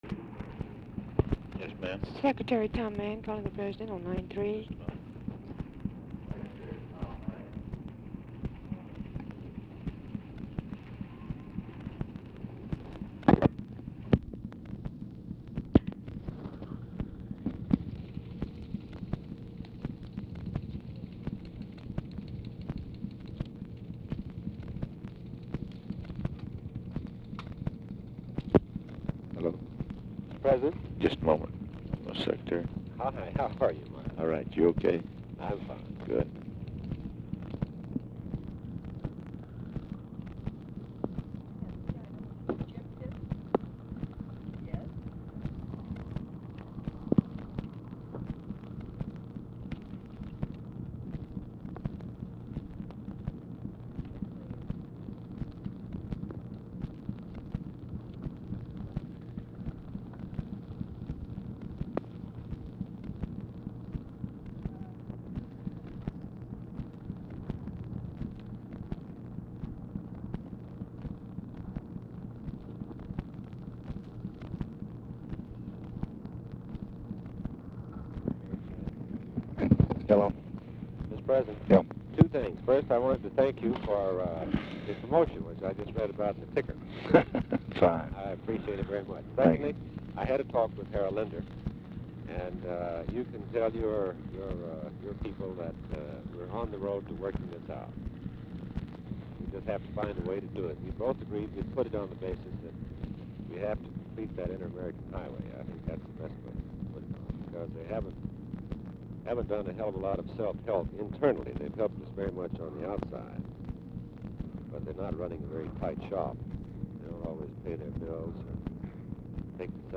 Telephone conversation # 8903, sound recording, LBJ and THOMAS MANN
MANN ON HOLD 1:20; WATSON IS MEETING WITH LBJ AT TIME OF CALL, GREETS MANN WHILE MANN IS ON HOLD FOR LBJ
Format Dictation belt
Oval Office or unknown location